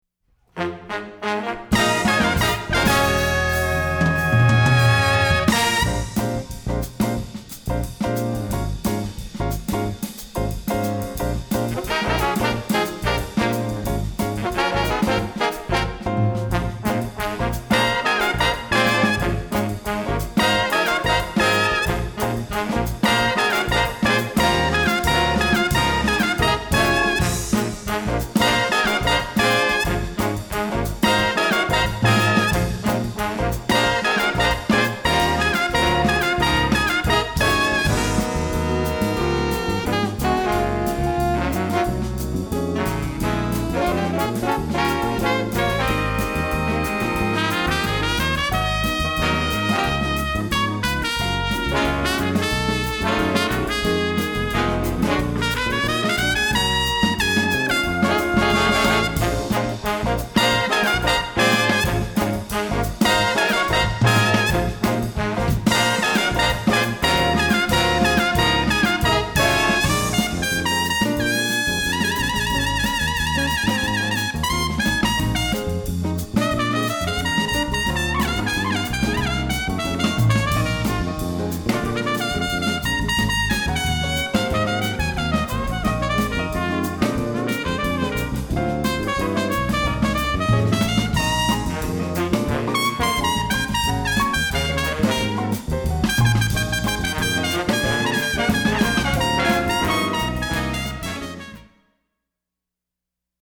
Voicing: Little Big Band